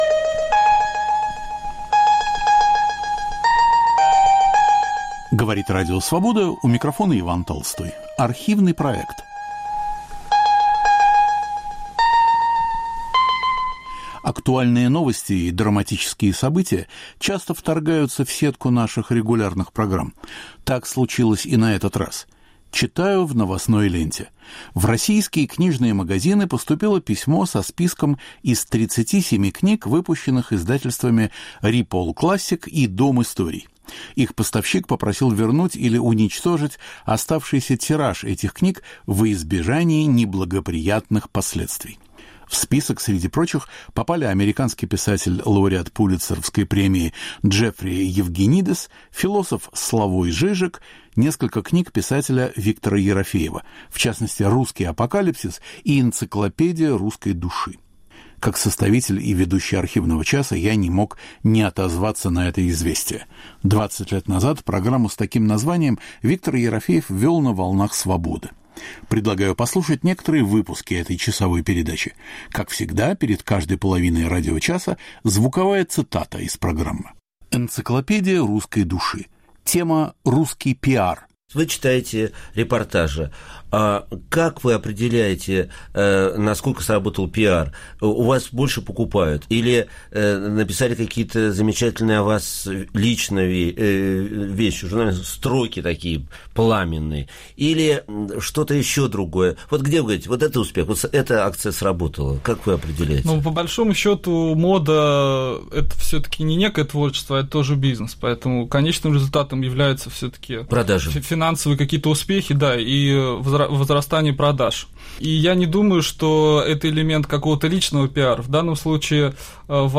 Автор и ведущий Виктор Ерофеев.